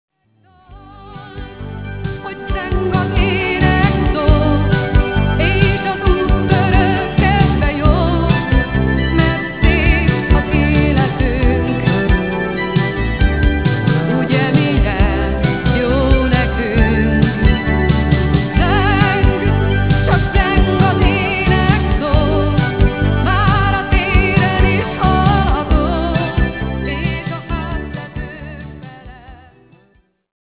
akusztikus gitár